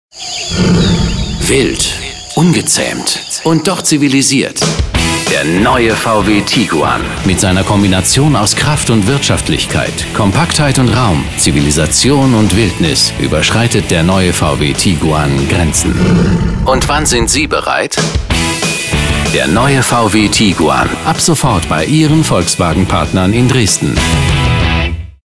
Profi-Sprecher deutsch.
Sprechprobe: Werbung (Muttersprache):
german voice over artist